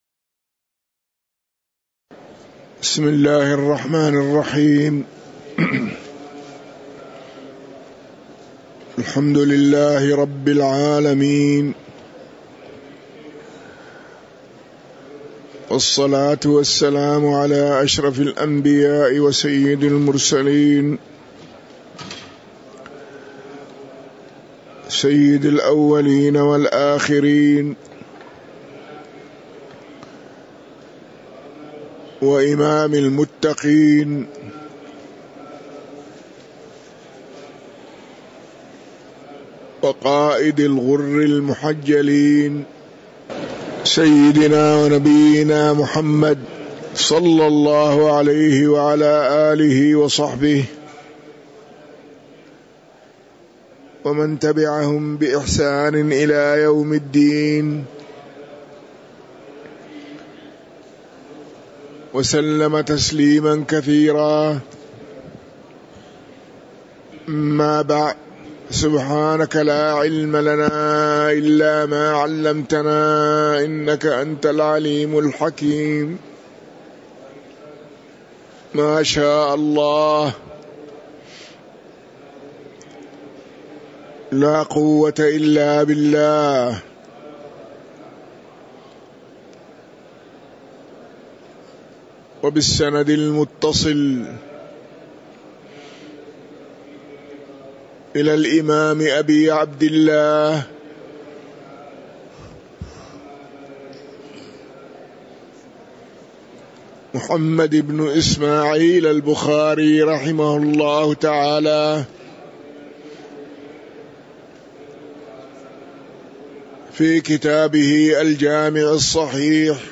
تاريخ النشر ٢٣ شوال ١٤٤٣ هـ المكان: المسجد النبوي الشيخ